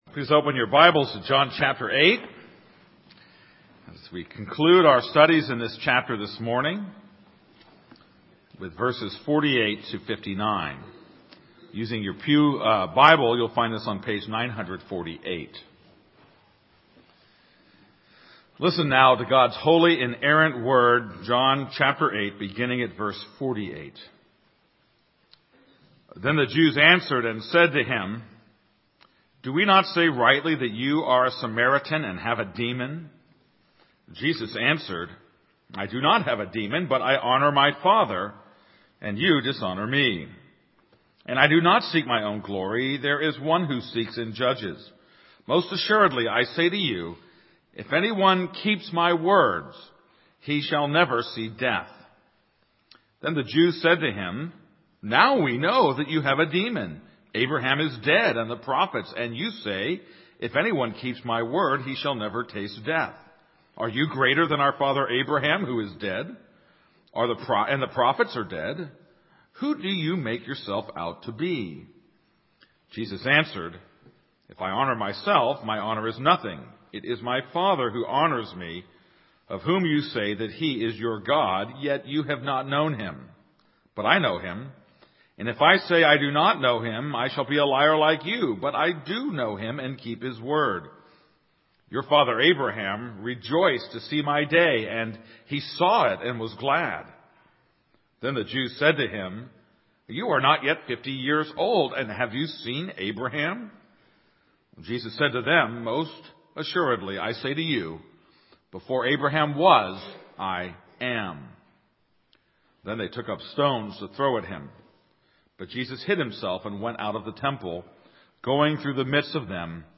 This is a sermon on John 8:48-59.